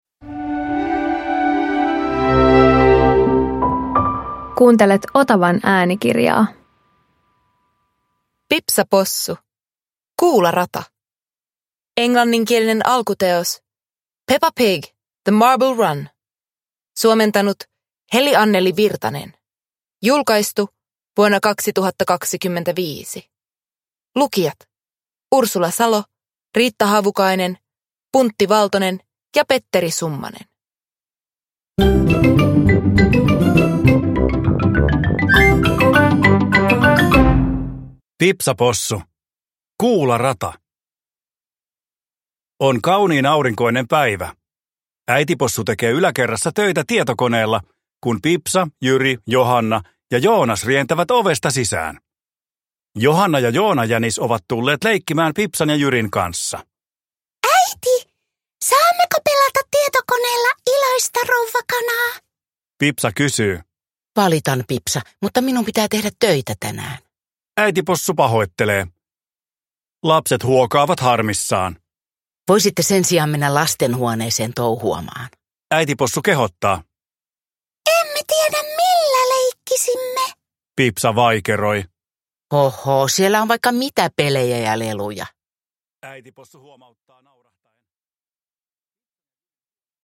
Pipsa Possu - Kuularata – Ljudbok